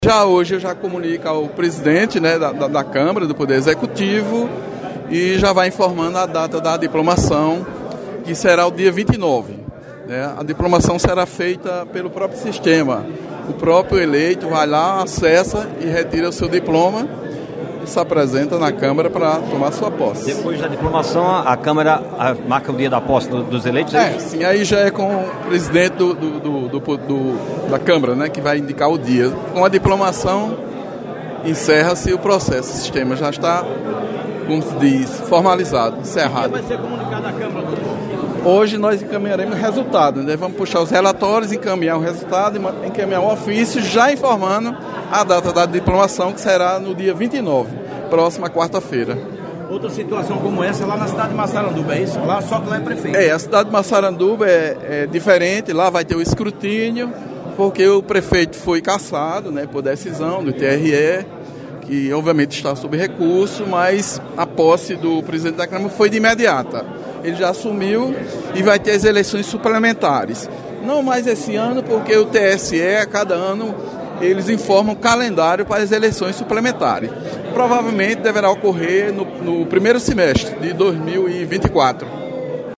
Em entrevista ao programa Correio Debate, da Rádio Correio 98 FM, o juiz Antônio Reginaldo deu detalhes do trâmite de diplomação dos novos parlamentares.